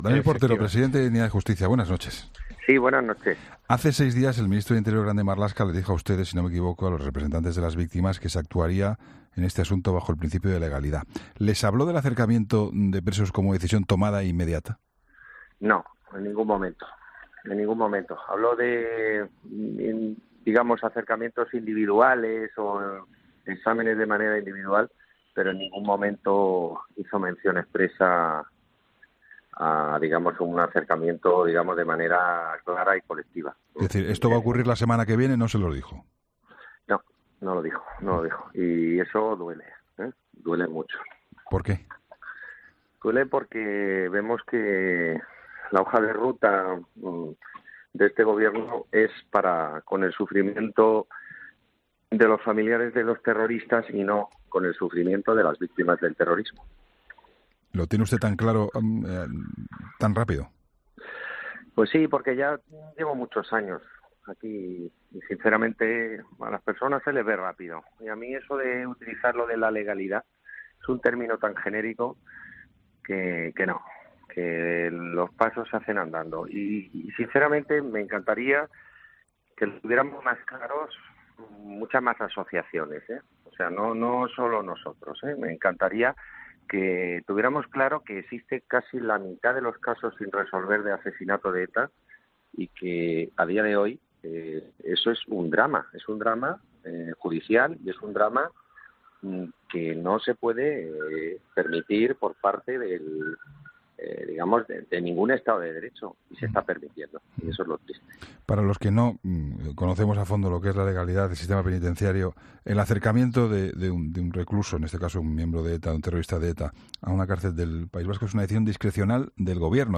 Entrevistas en La Linterna